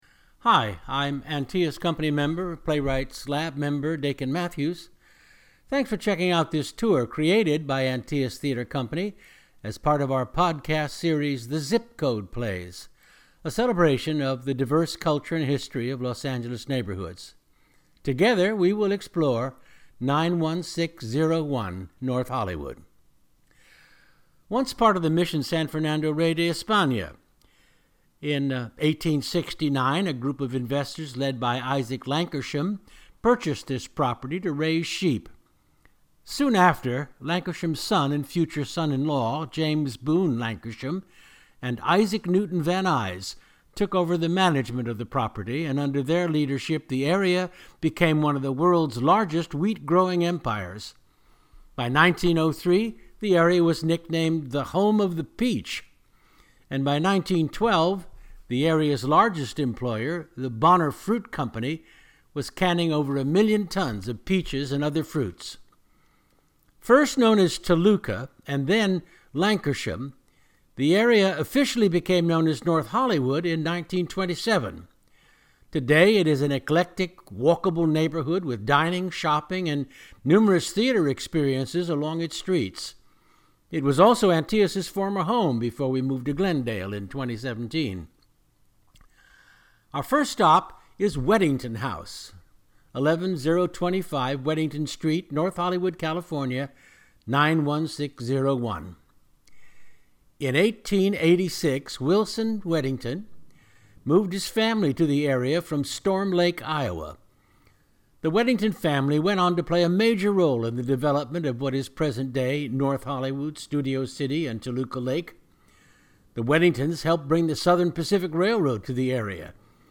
This Audio Tour is Narrated by Dakin Matthews (Bio) Highlights: Weddington House Moby's Organic Coffee & Tea Iliad Bookshop Television Academy City Kitchen Cafe NoHo Arts District Lankershim Train Depot Amelia Earhart Statue Open full audio Tour